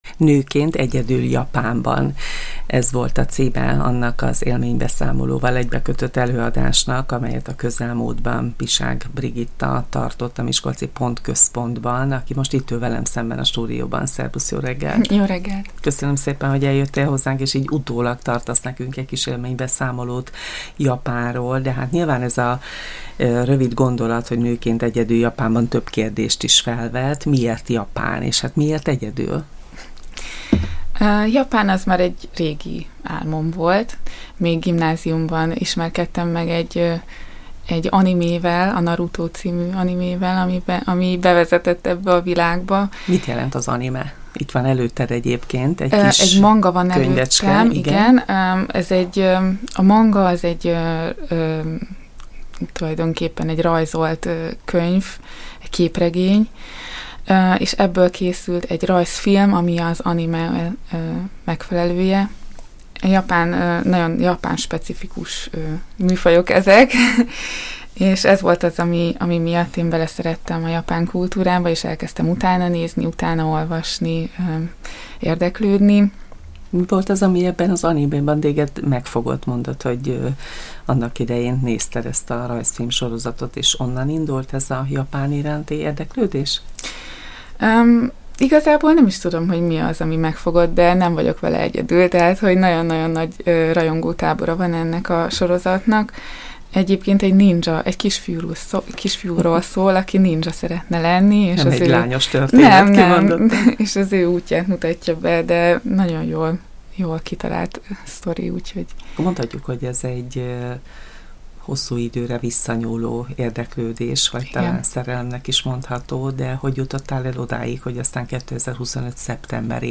A japán animék (rajzfilmek) vonzották figyelmét a távol-keleti országra, ezért is választotta úticéljául. Élménybeszámolóra hívtuk a Csillagpont Rádió stúdiójába.